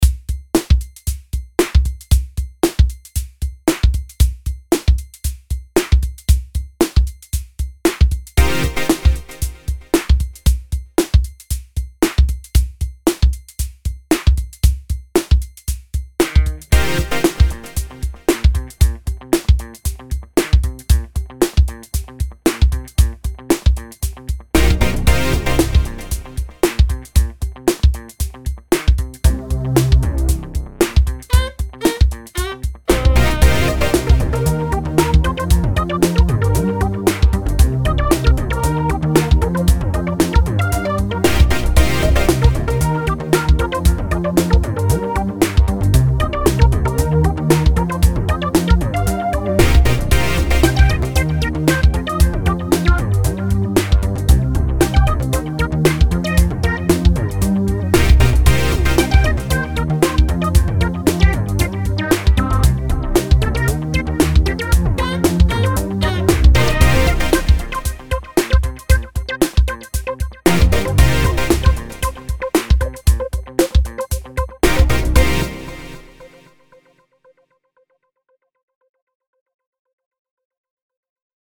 Ну блин чуть чуть подрулишь стандартный пресет, засвингуешь его и всё, понеслась... бас давай, лид давай и т. д...